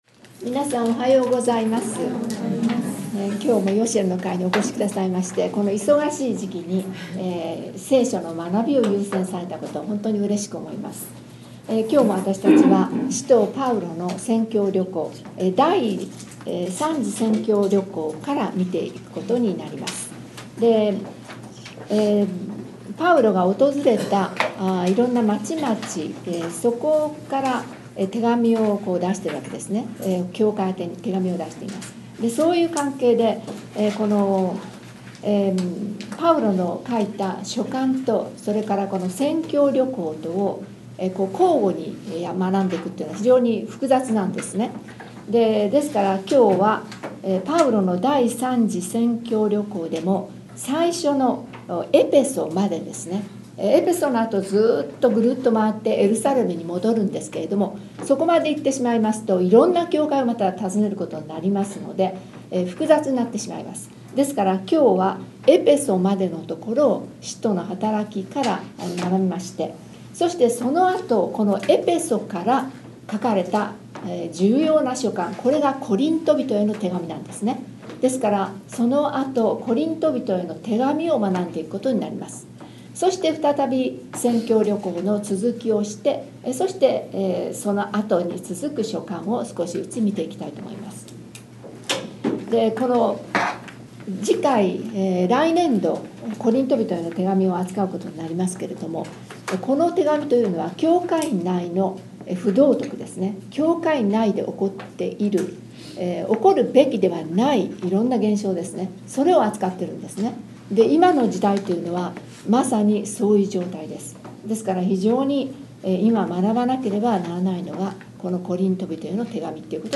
パウロの第三次宣教旅行「使徒の働き」18:23〜19:40 からのメッセージでした。